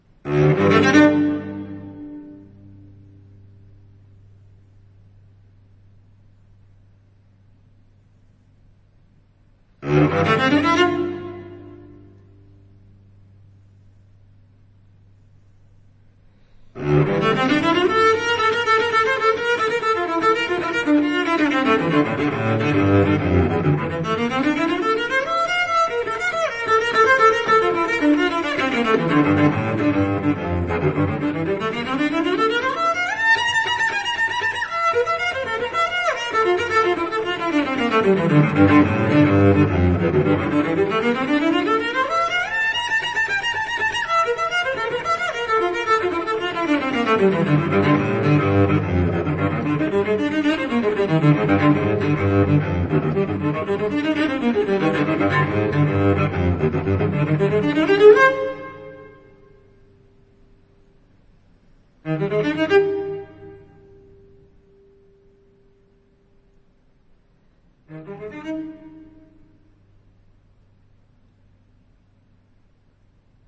這張現代大提琴作品，就以音樂家為標題，
所以在這張現場錄音的專輯裡，
這些作品，試圖脫離大提琴的傳統表現方式與音色。